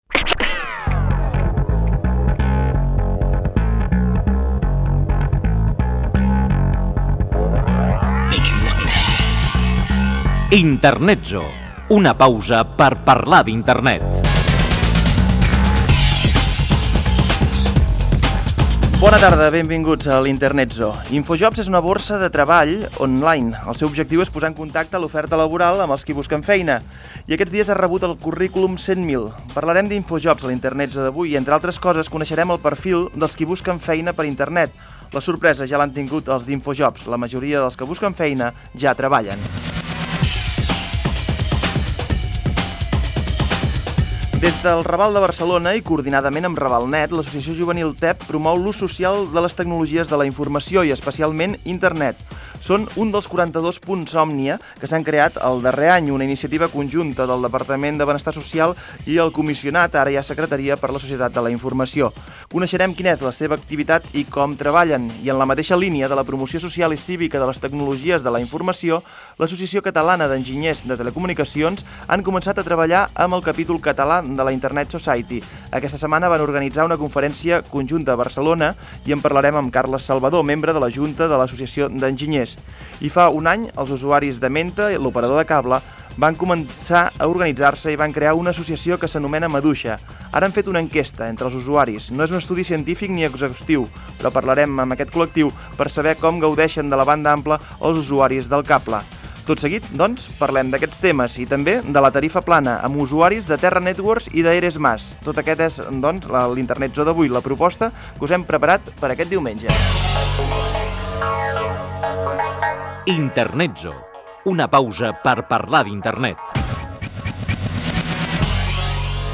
Careta del programa, presentació, Infojobs, el TED de Ravalnet i sumari del programa dedicat a les noves tecnologies.
Divulgació